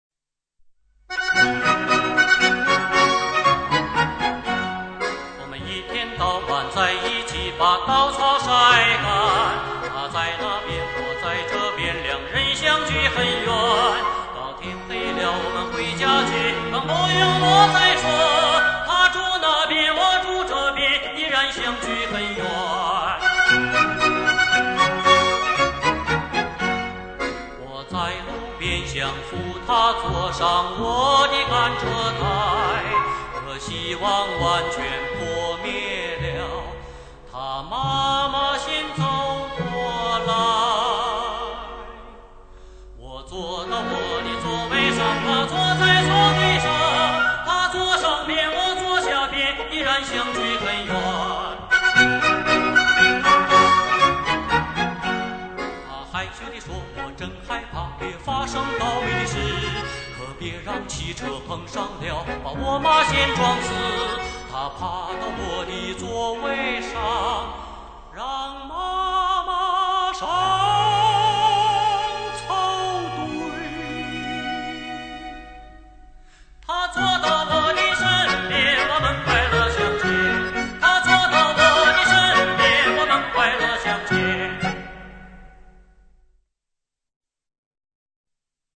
加拿大民歌